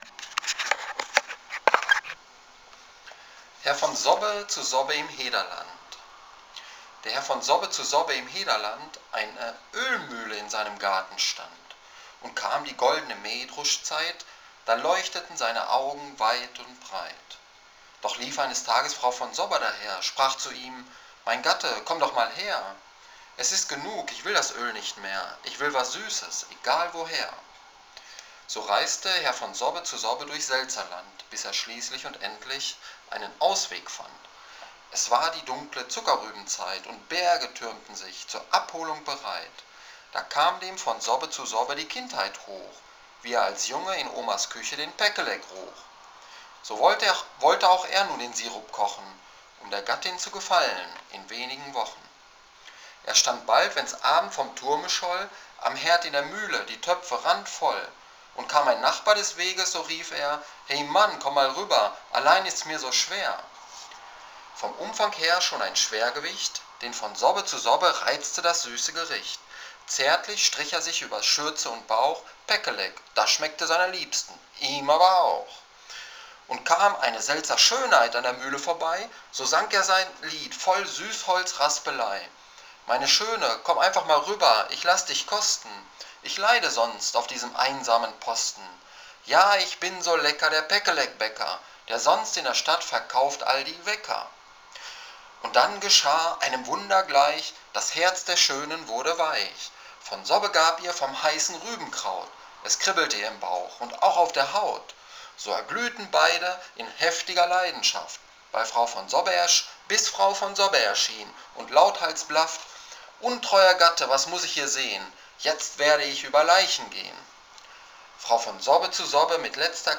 Ich dachte, liest Deinen treuen Homepagegästen mal was vor.